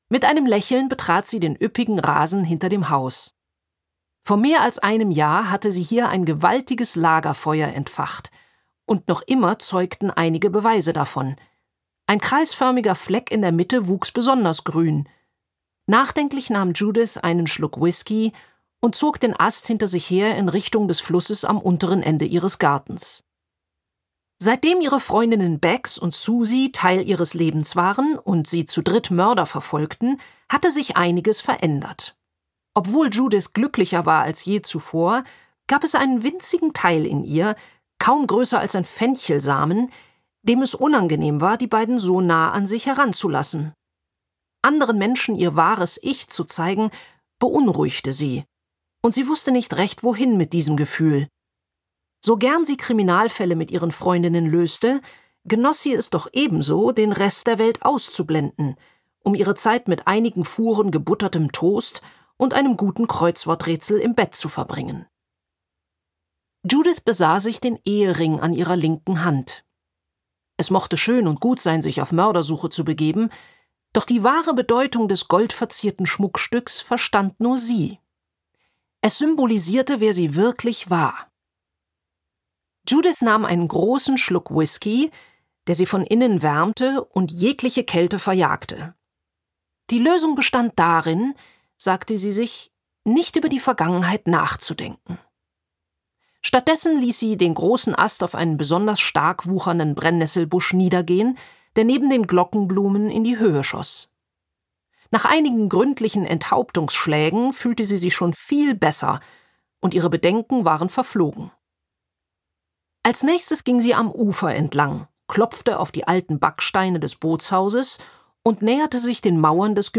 Die Kabarettistin und Schauspielerin Christine Prayon verkörpert Mrs Potts' und ihre Freunde so lebendig, dass es die reinste Hörfreude ist.
Gekürzt Autorisierte, d.h. von Autor:innen und / oder Verlagen freigegebene, bearbeitete Fassung.
Mrs Potts' Mordclub und der Tote in der Themse Gelesen von: Christine Prayon